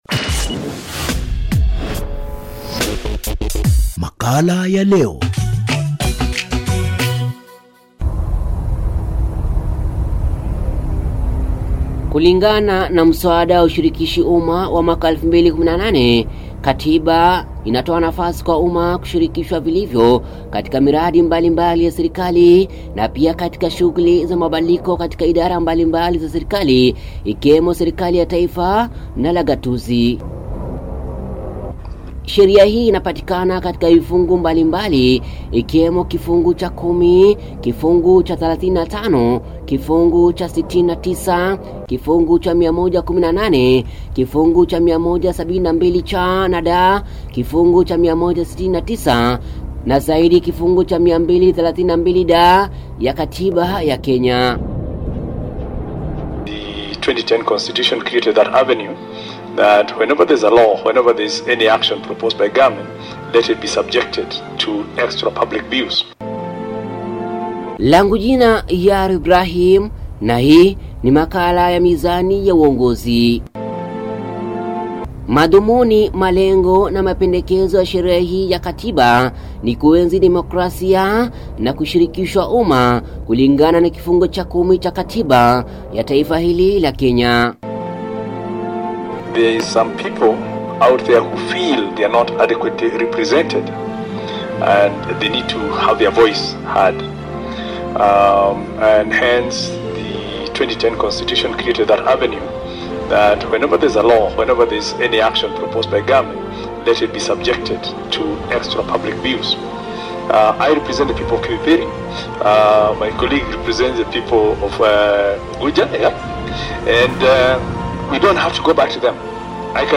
Radio feature